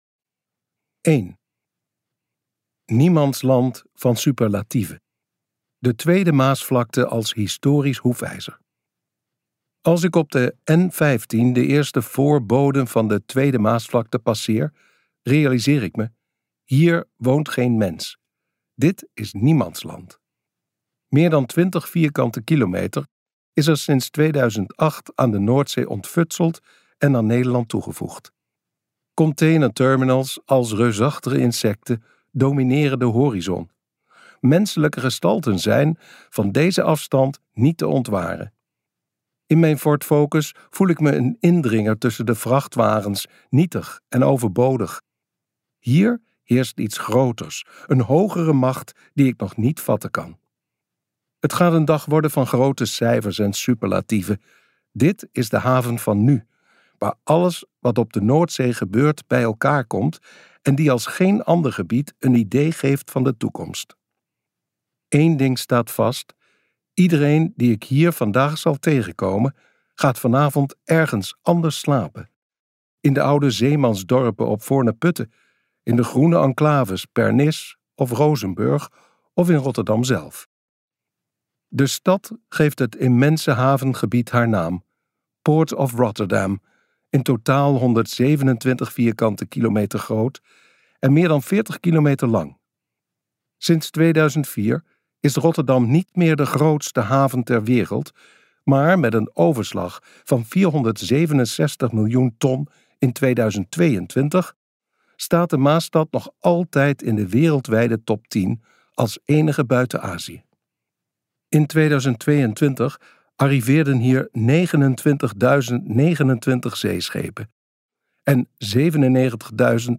Uitgeverij Omniboek | Biografie van de noordzee luisterboek